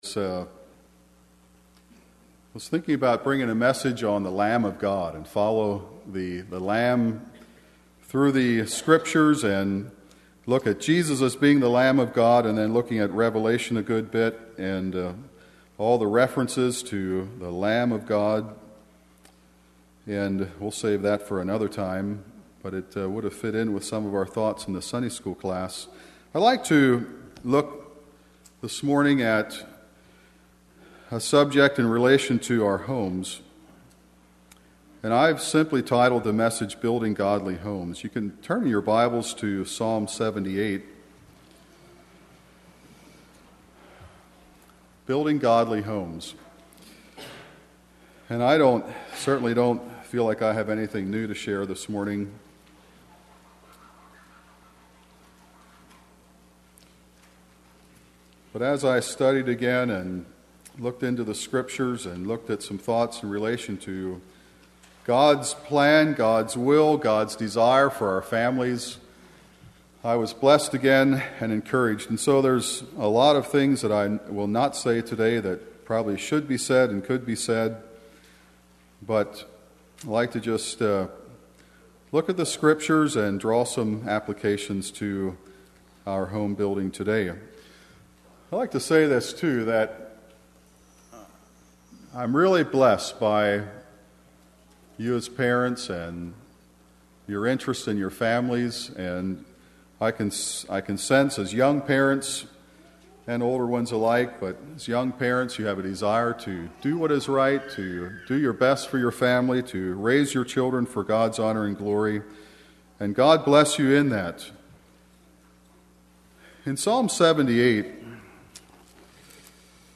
2021 Sermon ID